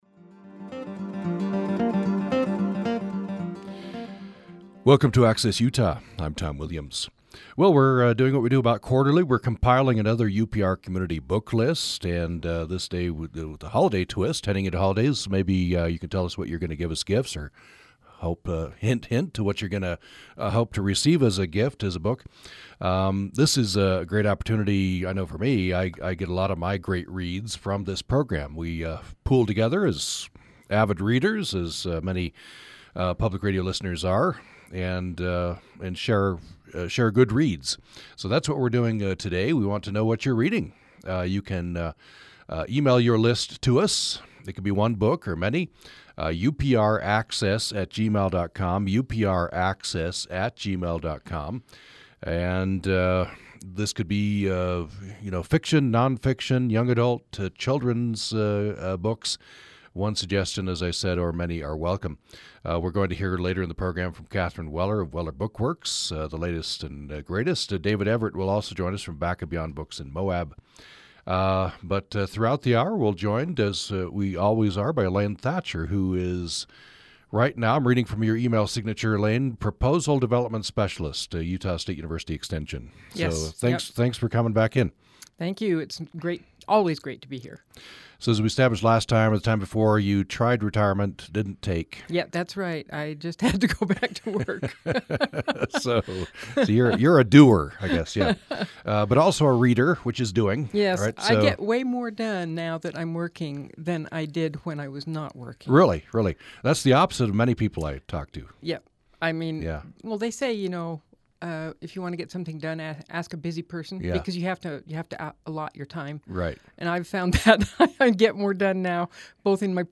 Access Utah is UPR's original program focusing on the things that matter to Utah. The hour-long show airs live Monday-Thursday at 9:00 a.m. Access Utah covers everything from pets to politics in a range of formats from in-depth interviews to call-in shows.